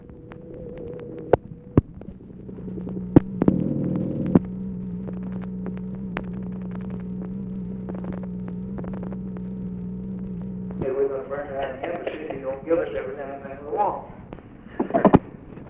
OFFICE CONVERSATION, January 29, 1964
Secret White House Tapes | Lyndon B. Johnson Presidency